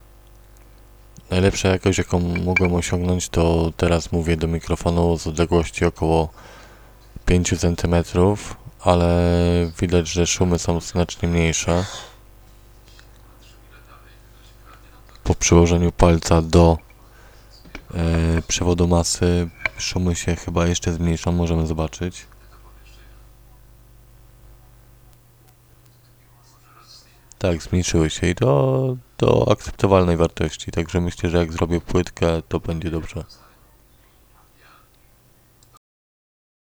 A w poniżeszj próbce wzmocnienie jest ustawione praktycznie na minimum. Trzeba mówić bliżej mikrofonu ale za to zmniejszają się szumy.
TEST – brzmienie przedwzmacniacza mikrofonowego po wyregulowanym wzmocnieniu